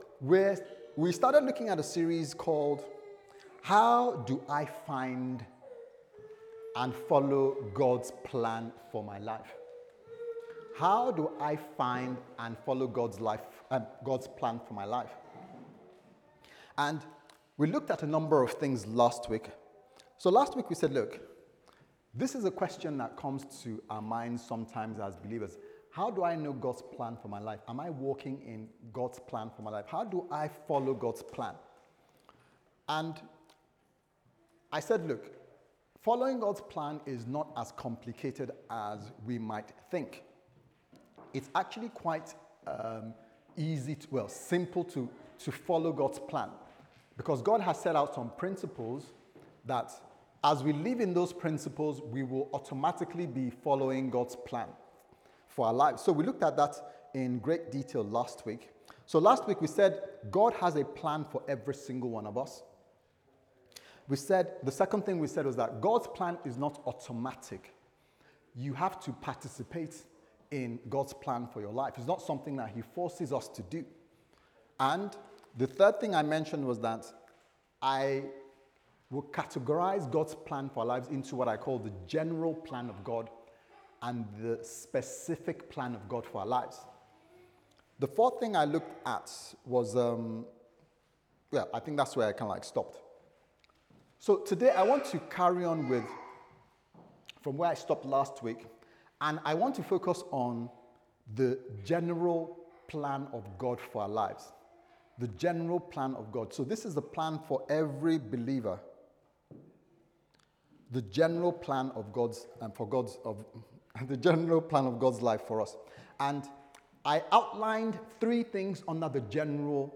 How Can I Find God's Will For My Life Service Type: Sunday Service Sermon « How Do I Find And Follow God’s Plans For My Life